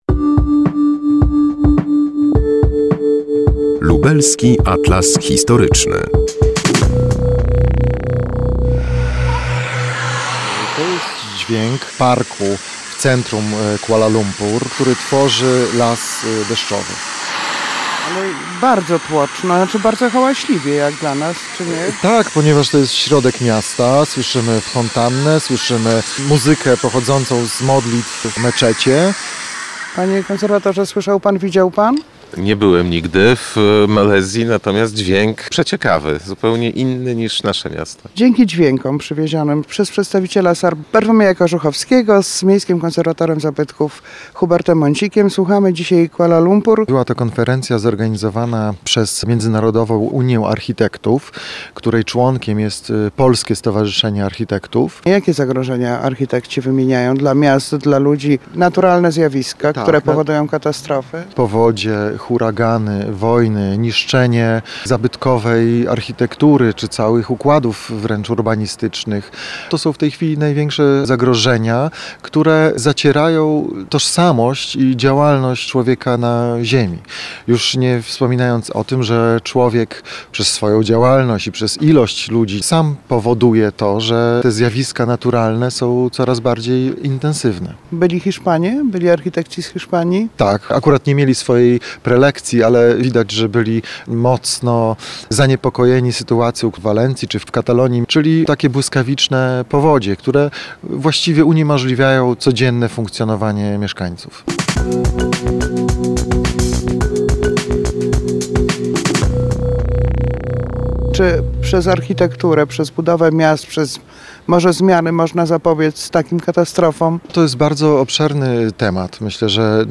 słuchamy dzisiaj Kuala Lumpur.